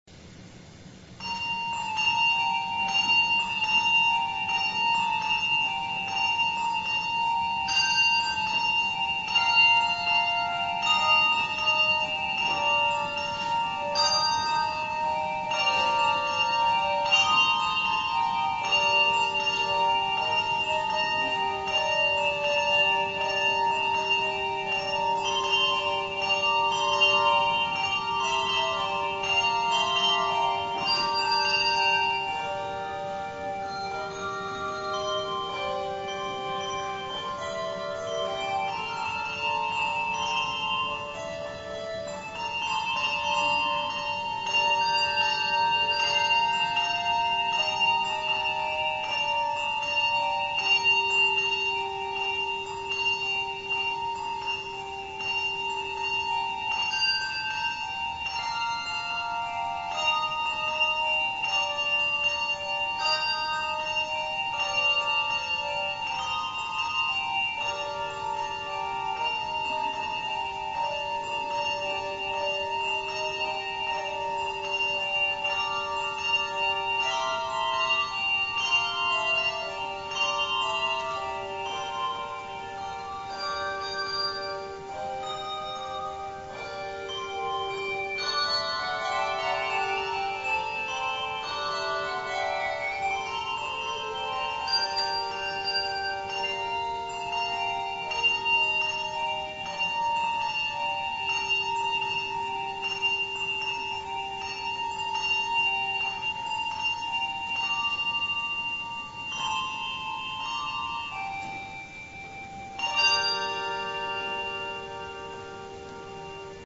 The Second Reformed Junior Handbell Choir plays "Carol of the Bells" as arranged by Kristine Johanek
Handbell Music